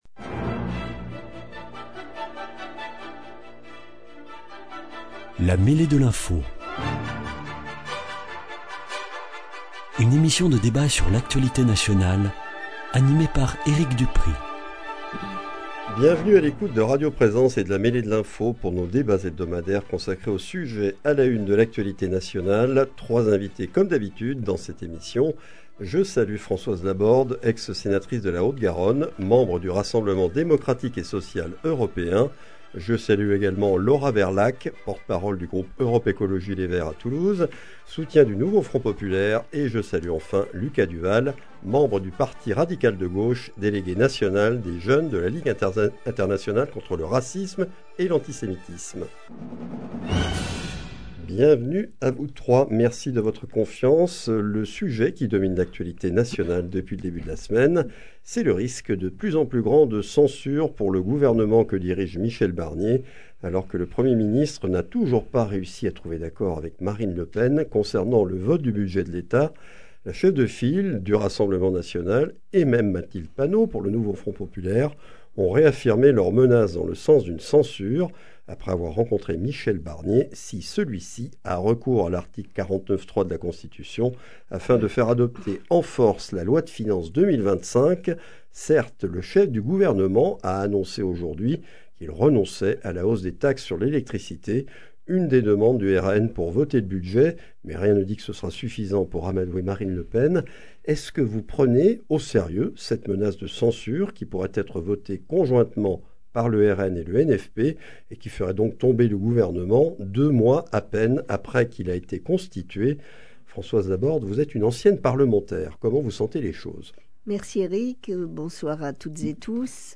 Dans cette émission, nous débattons du risque de censure grandissant pour le gouvernement Barnier, puis de la proposition des députés LFI d’abroger le délit d’apologie du terrorisme.